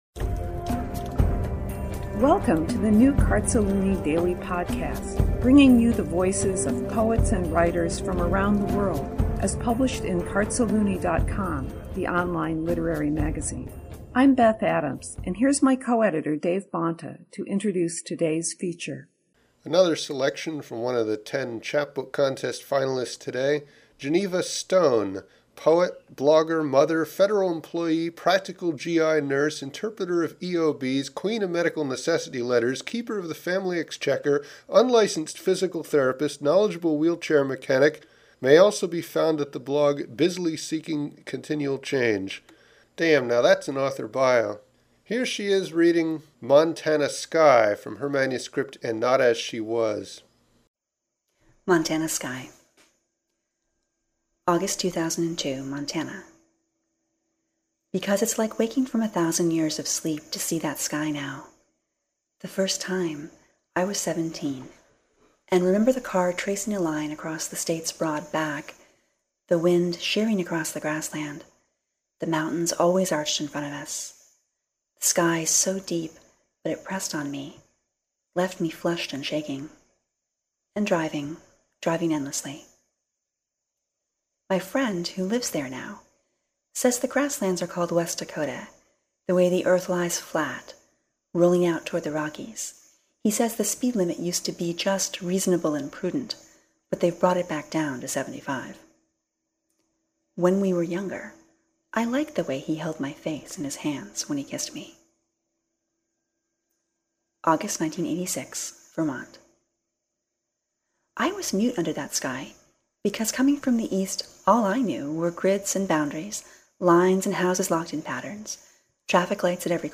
Now I’ve listened to it and just love your voice. So soft and powerful.
I love this poem. And what a performance of it.